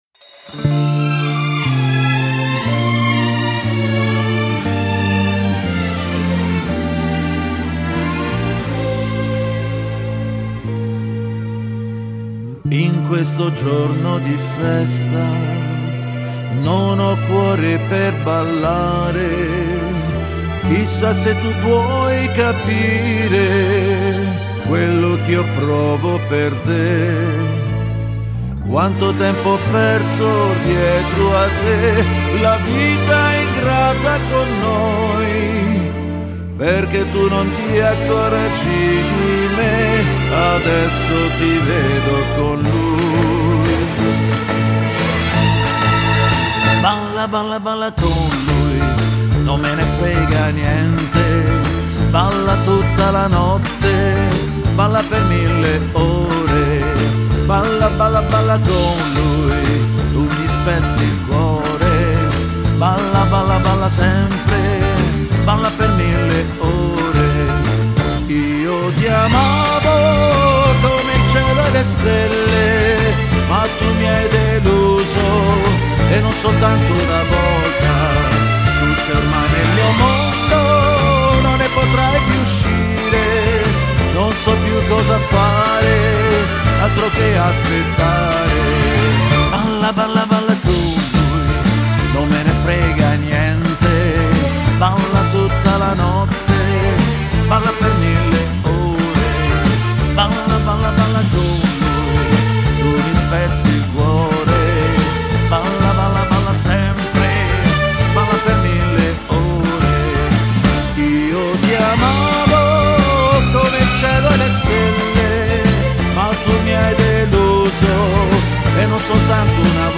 Canzone Italiana